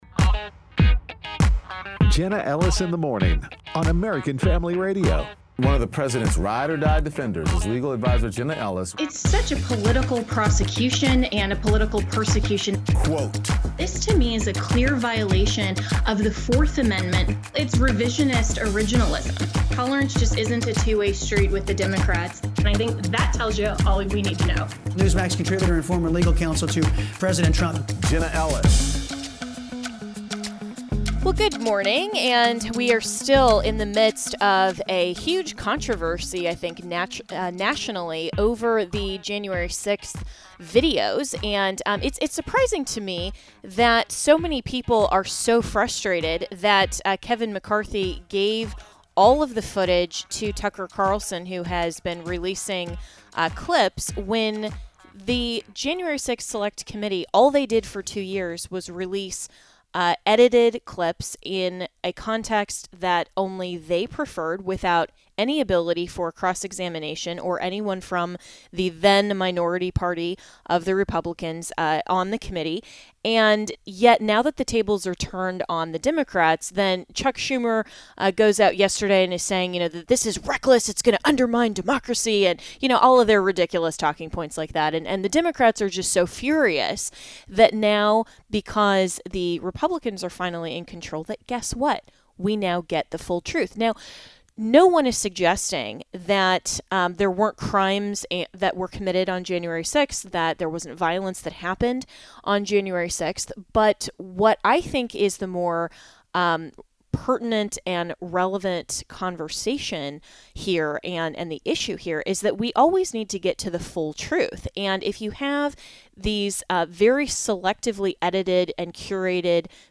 Interviews With Rep.